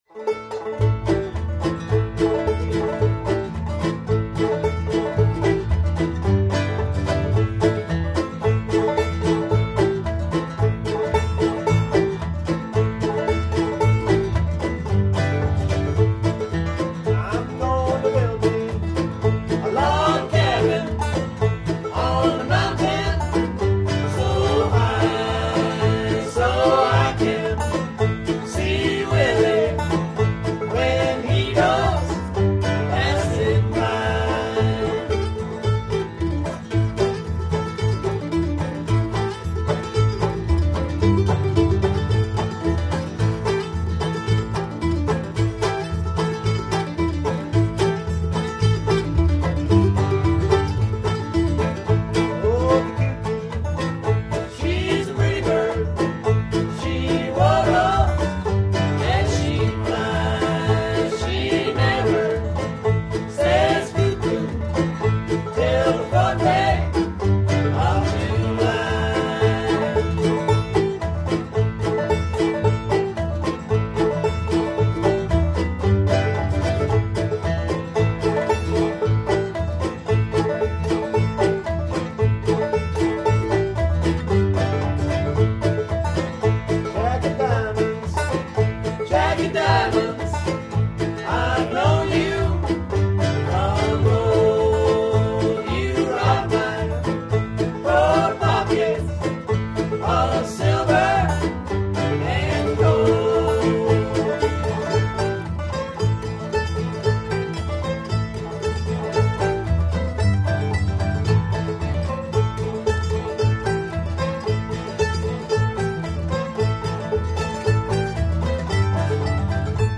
Little Rabbit is a band that performs old-time American songs and fiddle tunes.
banjo
guitar
bass
mandolin
All of us sing.